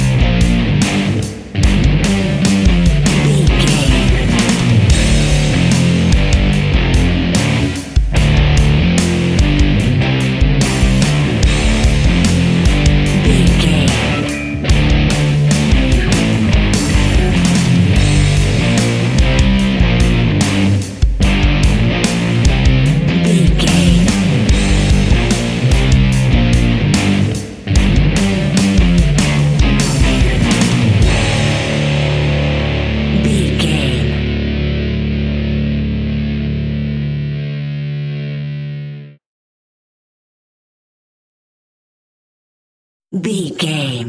Powerful Rock Music Cue 30 Sec Mix.
Fast paced
Aeolian/Minor
heavy rock
distortion
rock instrumentals
rock guitars
Rock Bass
heavy drums
distorted guitars
hammond organ